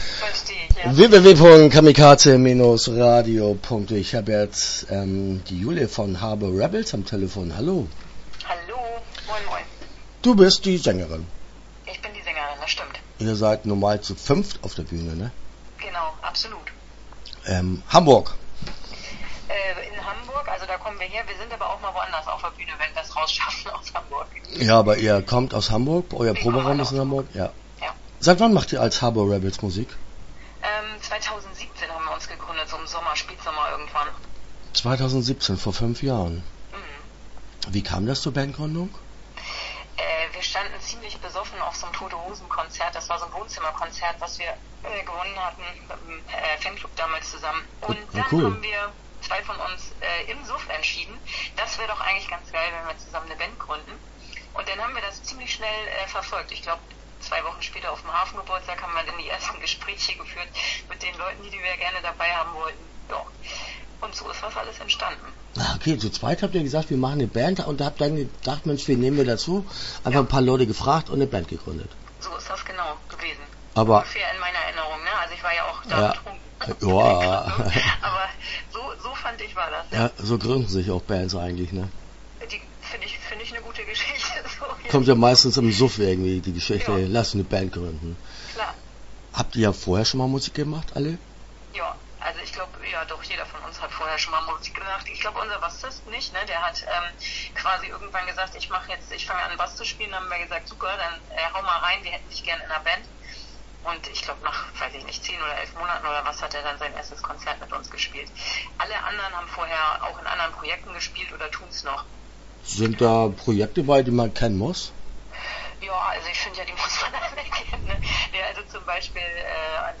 Start » Interviews » Harbour Rebels